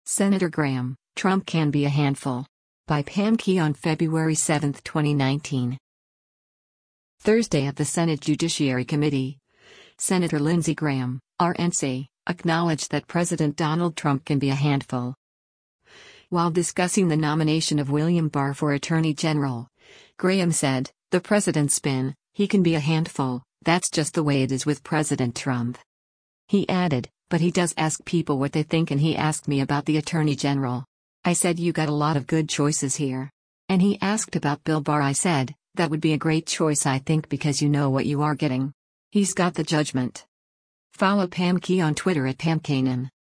Thursday at the Senate Judiciary Committee, Sen. Lindsey Graham (R-NC) acknowledged that President Donald Trump “can be a handful.”